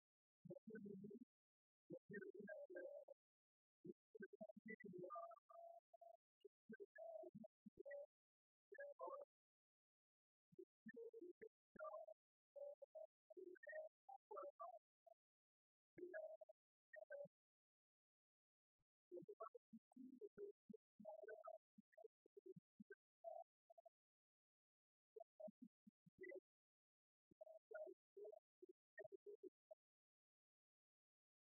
Allocution de M. Jean Moreau, maire
discours au Sporting lors de la cérémonie de jumelage avec Münsingen